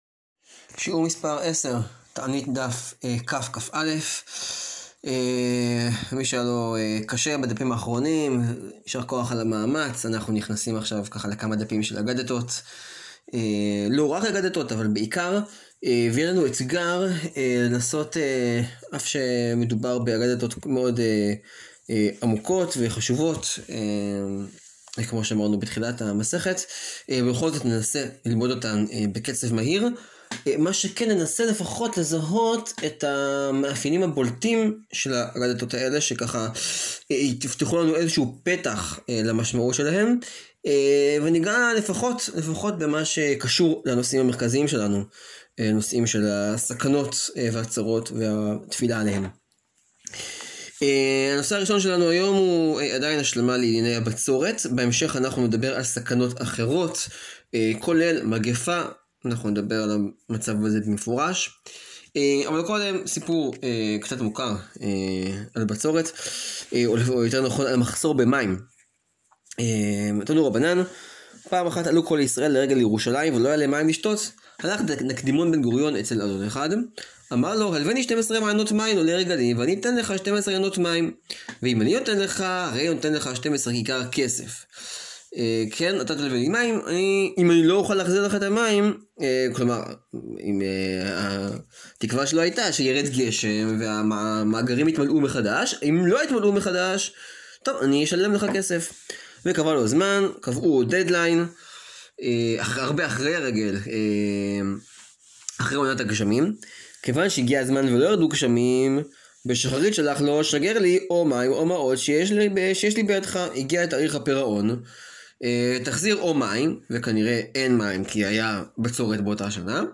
שיעור 10 להאזנה: מסכת תענית, דפים כ-כא.